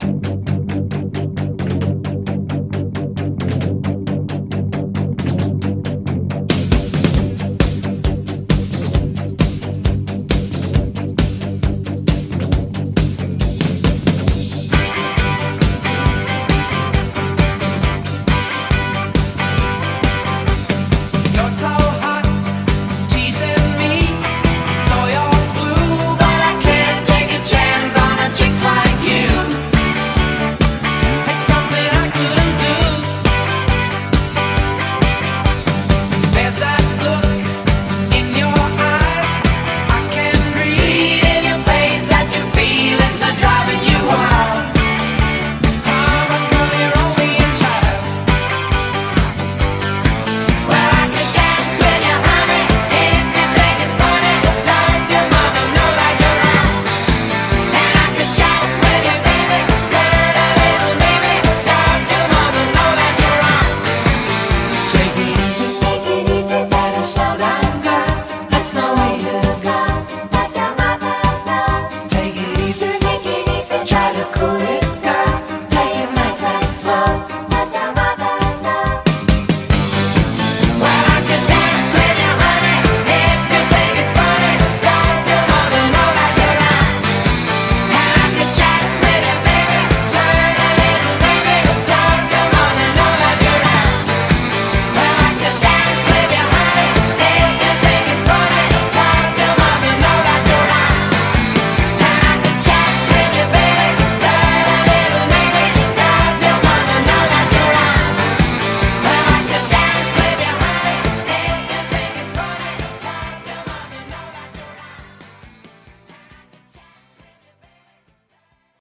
BPM132-138
Audio QualityMusic Cut